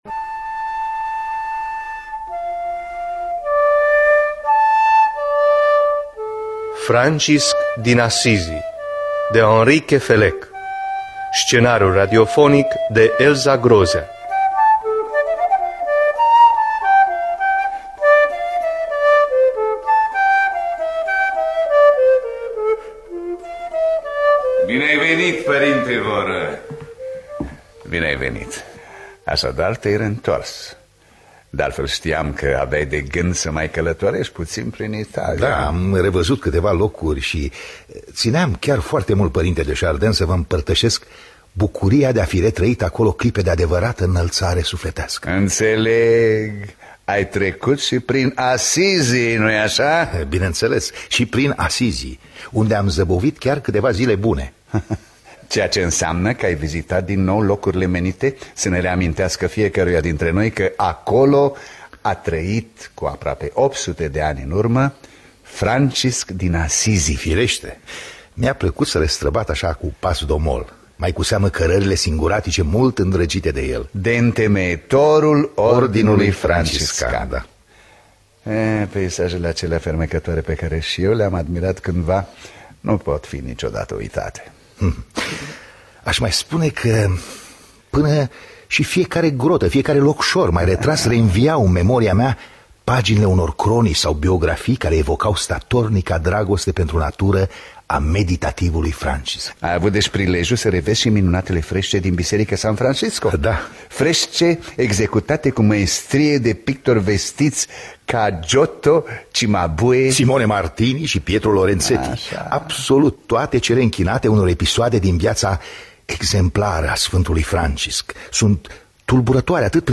Biografii, Memorii: Henry Queffellec – Francisc Din Assisi (2001) – Teatru Radiofonic Online